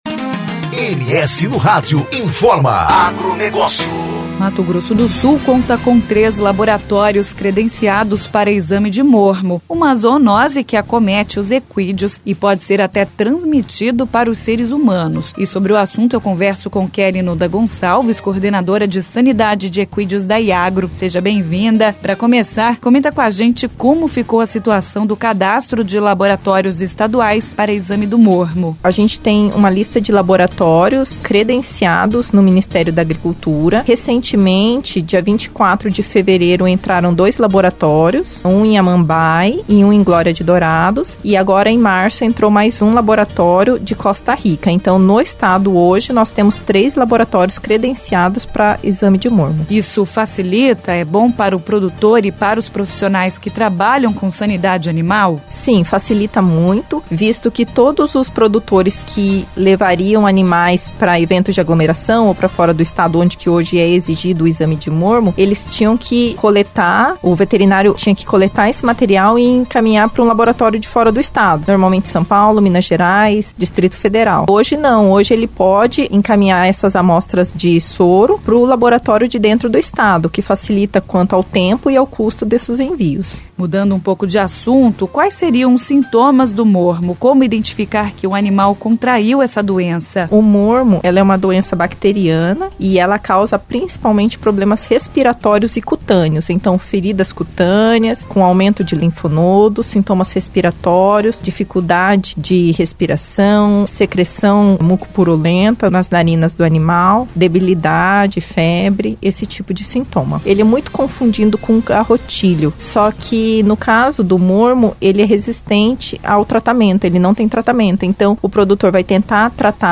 Entrevista: Técnica da Iagro fala sobre diagnóstico e sintomas do mormo, doença comum em equídeos – Agência de Noticias do Governo de Mato Grosso do Sul